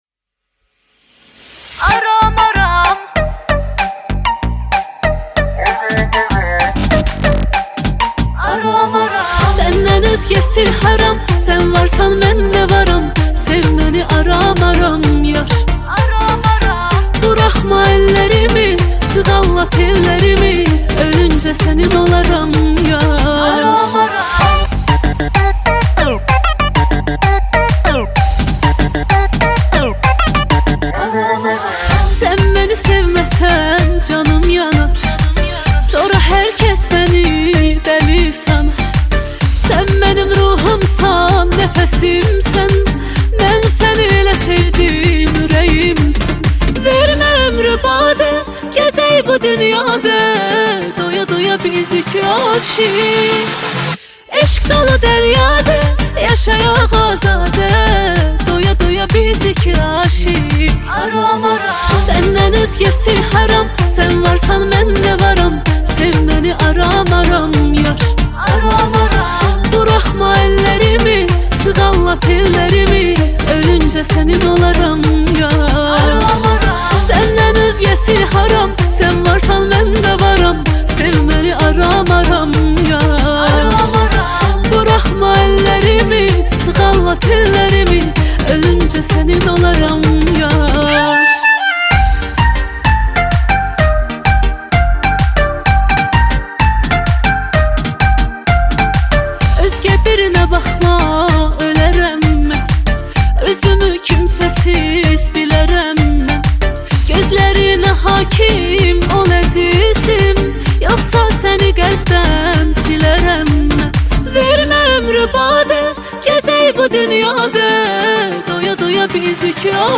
موزیک آذربایجانی